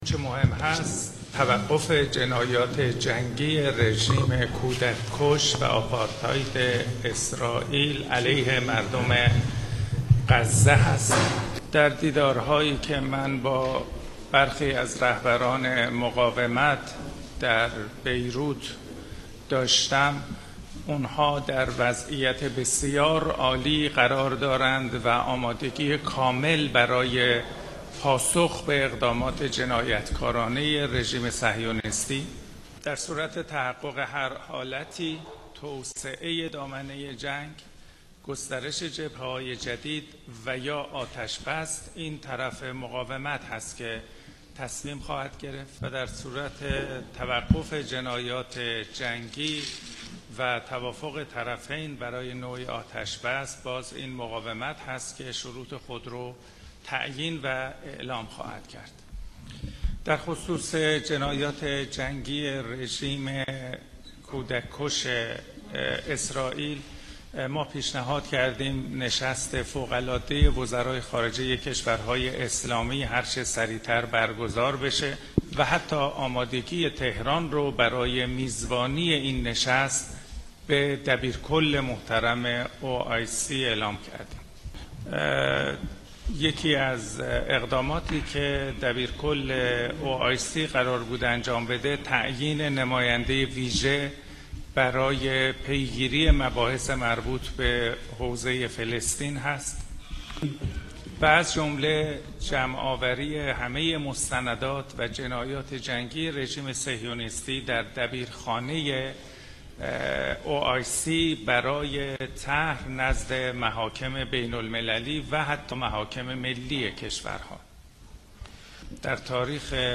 نشست خبری امیرعبداللهیان در بیروت